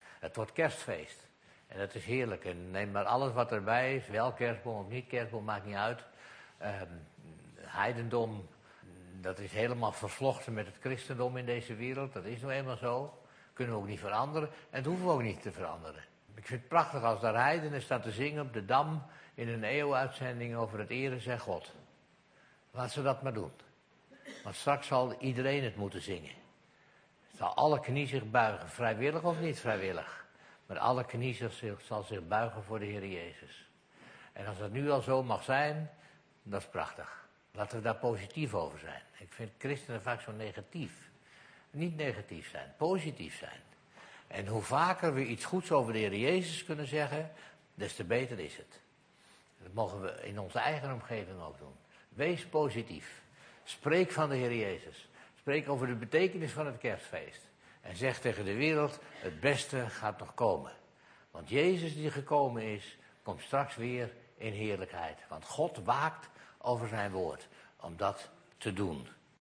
verkondiging